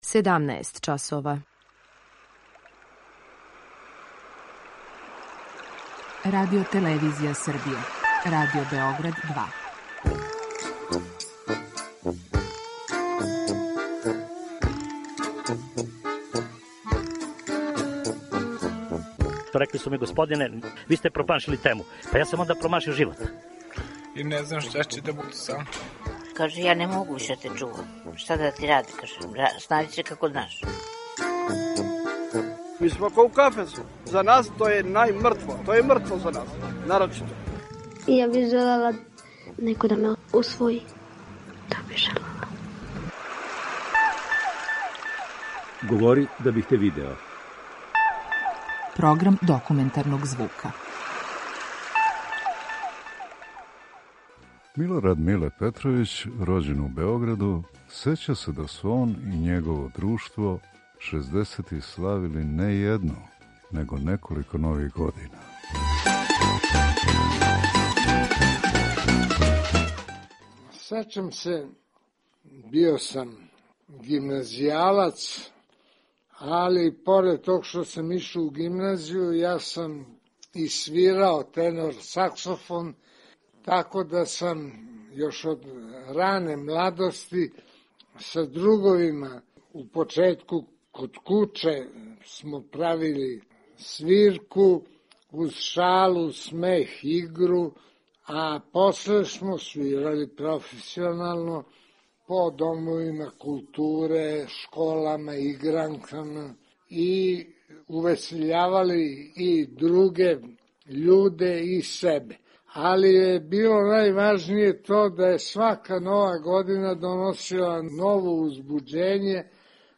Документарни програм
Серија полусатних документарних репортажа